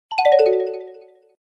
на уведомление , без слов